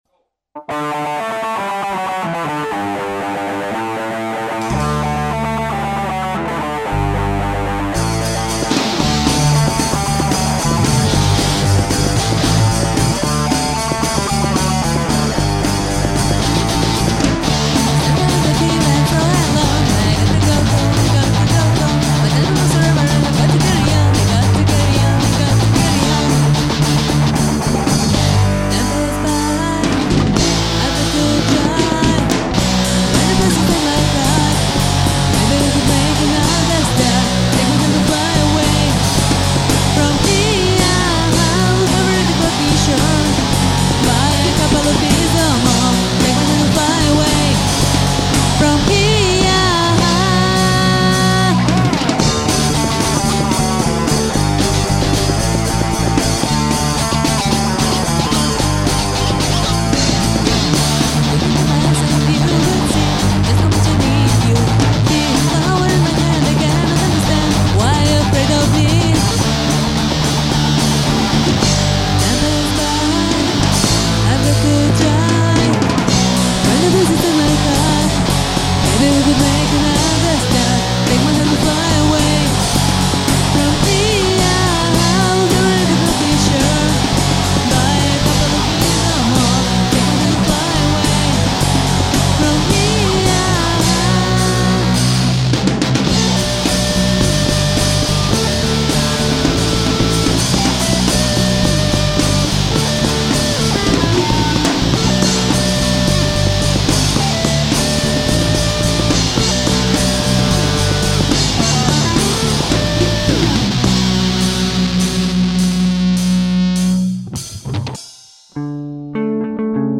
Punk!